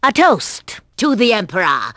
Voice: Eartha Kitt
Lanky, shrill and very, very old, Yzma is not your typical Disney villain.